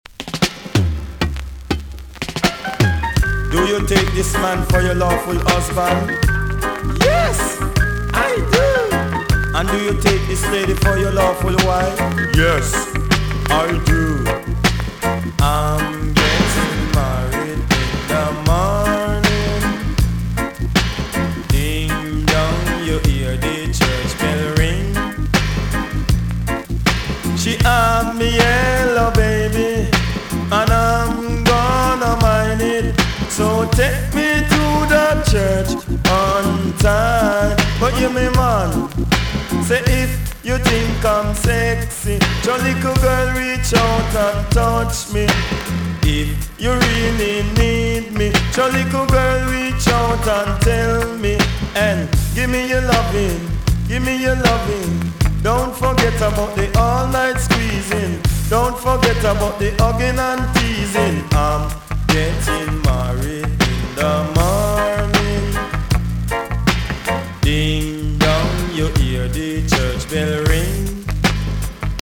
TOP >80'S 90'S DANCEHALL
VG+ 少し軽いチリノイズが入ります。